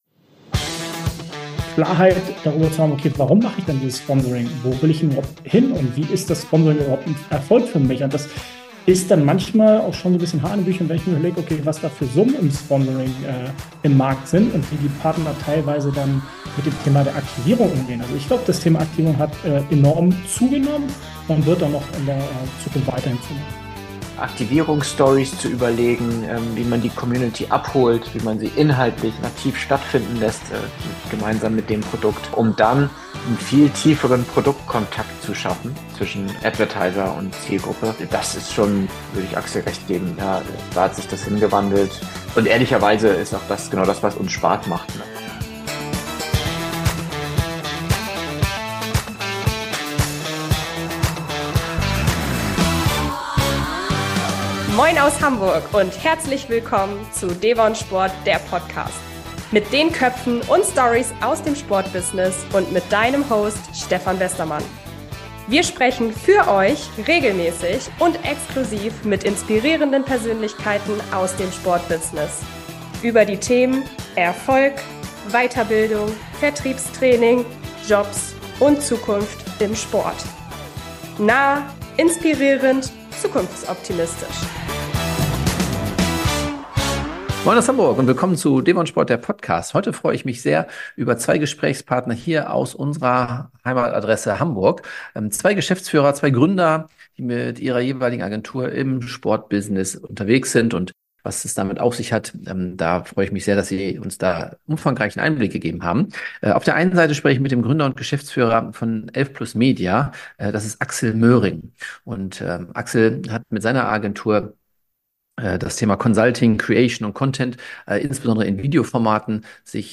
Sponsoring ist essentiell im Profisport und wichtig für viele Vereine, Clubs und Organisationen. In der heutigen Folge berichten gleich 2 Experten darüber, was erfolgreiche Aktivierung im Sponsoring ausmacht: Erfahre mehr über die Erfolgsgeschichte von 11...